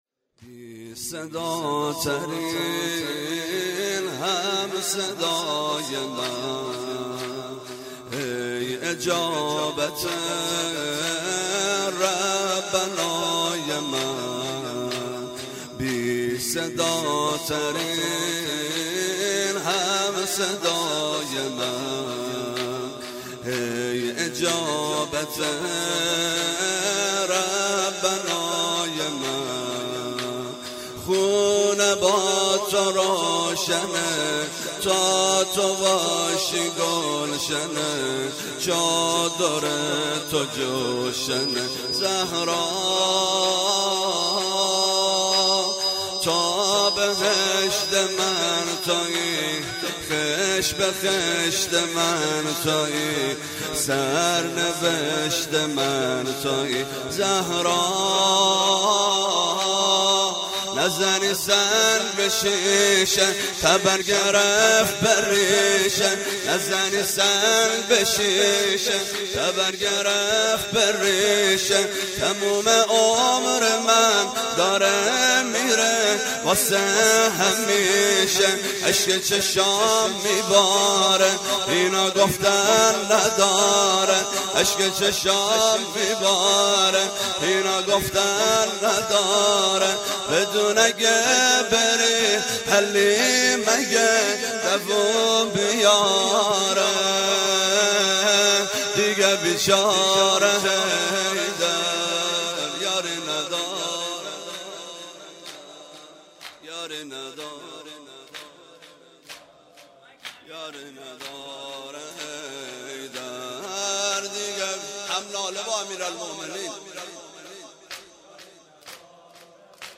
هیئت زواراباالمهدی(ع) بابلسر
0 0 زمینه - بی صداترین هم صدای من
شب اول ویژه برنامه فاطمیه دوم ۱۴۳۹